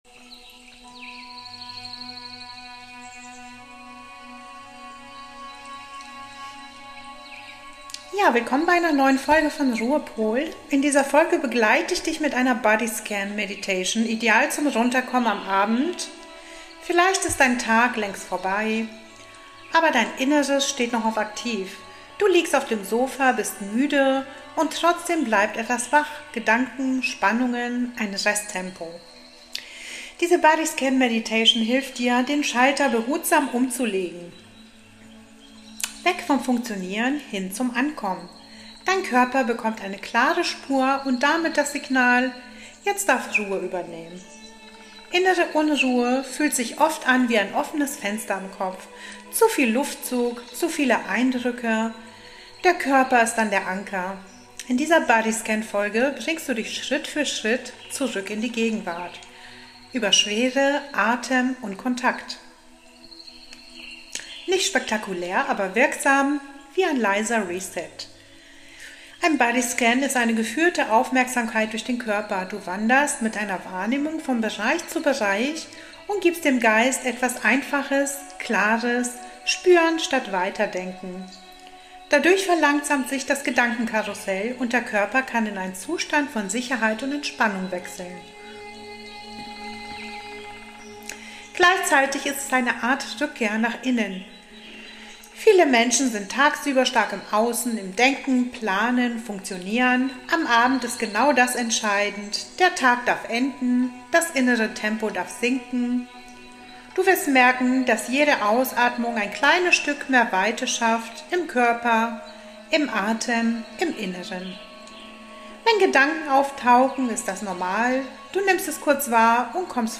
Body-Scan am Abend: Runterkommen & Nervensystem beruhigen In
dieser Folge • Geführte Aufmerksamkeit durch den Körper • Gedanken